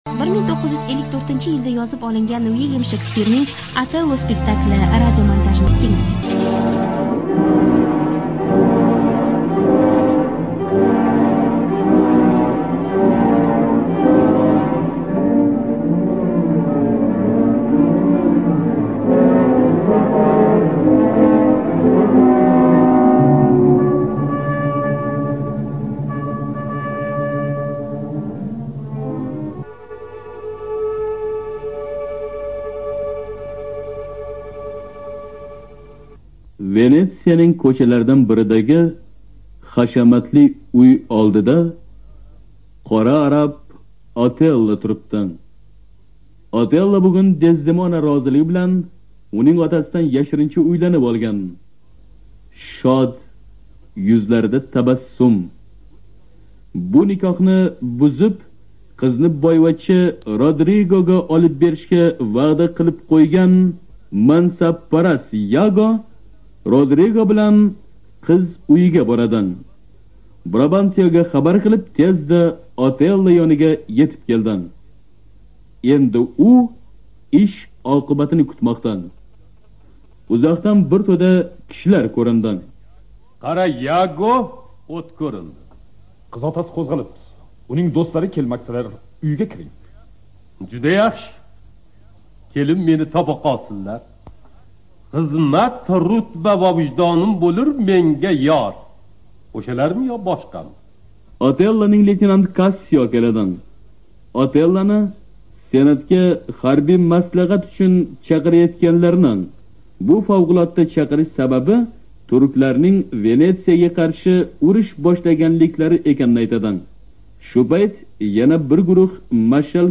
ЖанрРадиоспектакли на узбекском языке